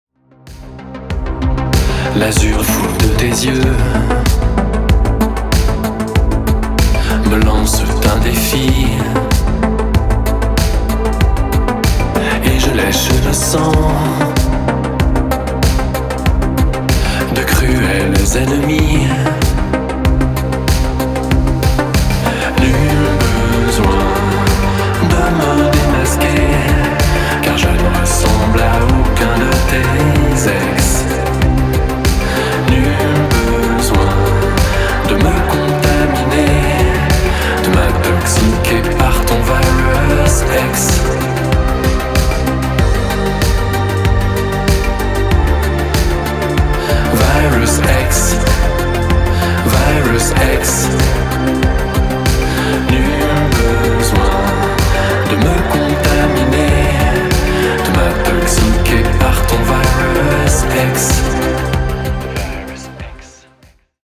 Italo Disco